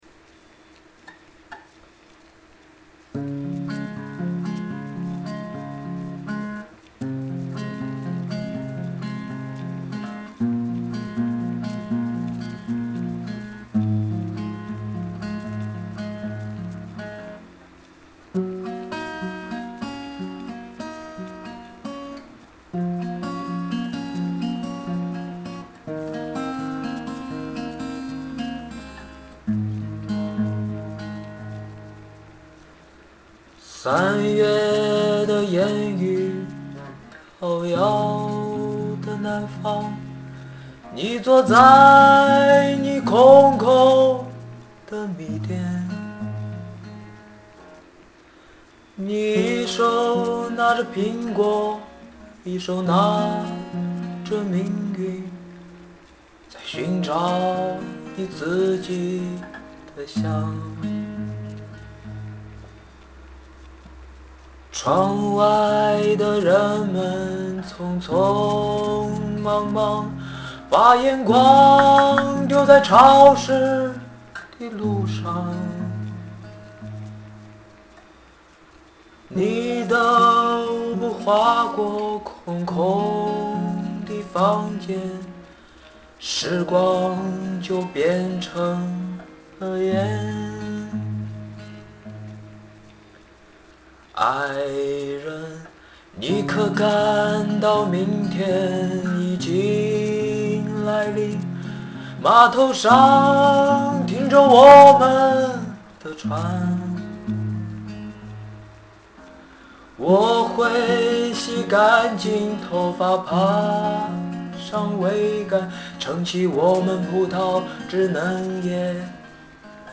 曲风：抒情